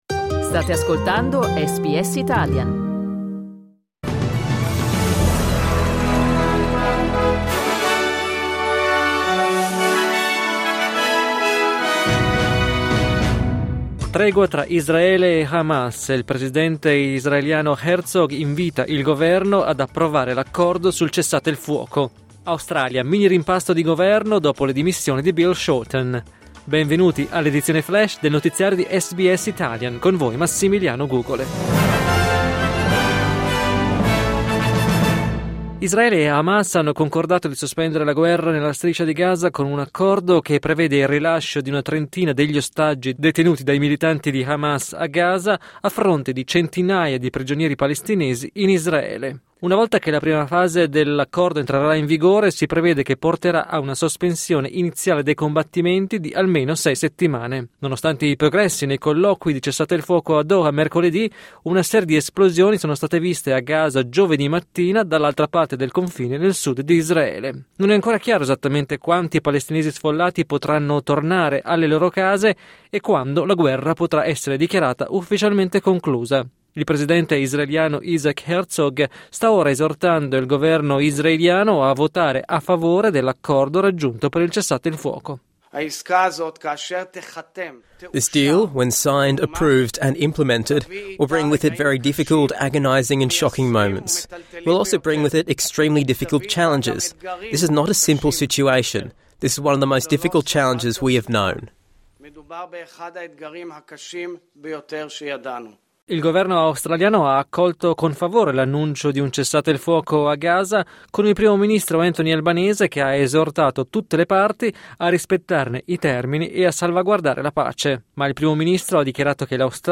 News flash giovedì 16 gennaio 2025
L’aggiornamento delle notizie di SBS Italian.